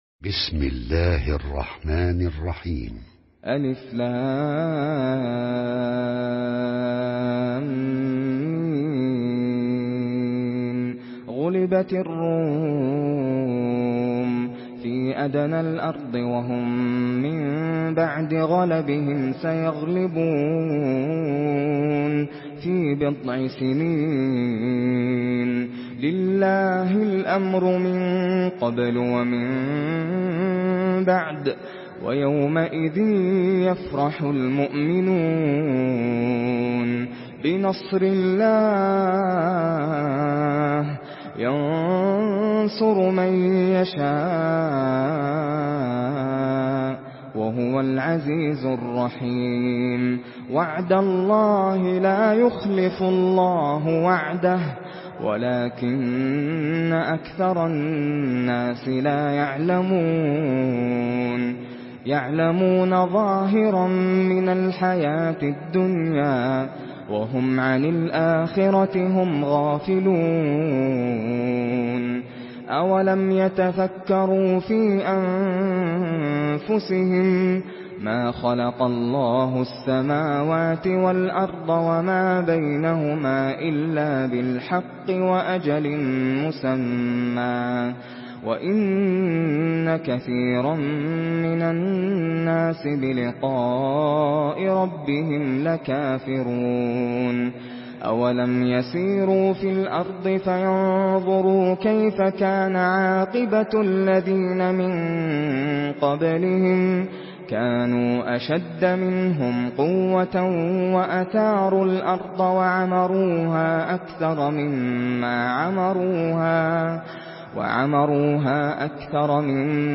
Surah Ar-Rum MP3 by Nasser Al Qatami in Hafs An Asim narration.
Murattal